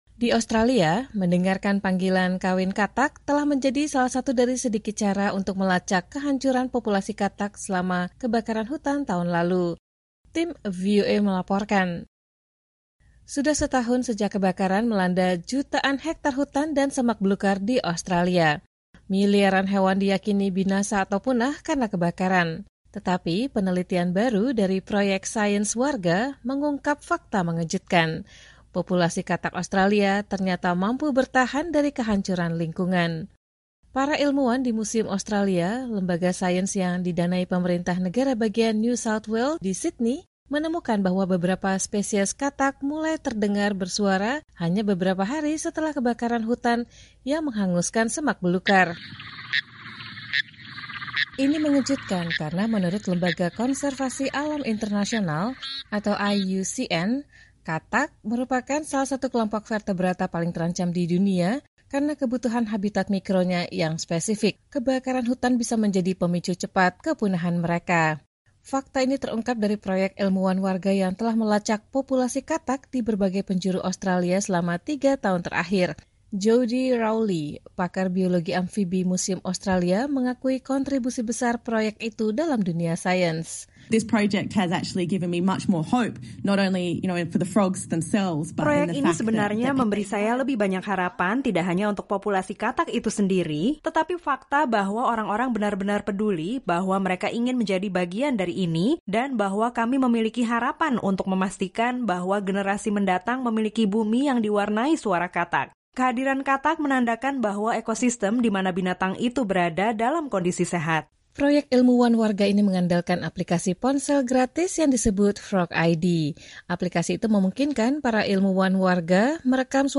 Di Australia, mendengarkan panggilan kawin katak telah menjadi salah satu dari sedikit cara untuk melacak kehancuran populasi katak selama kebakaran hutan tahun lalu. Tim VOA melaporkan.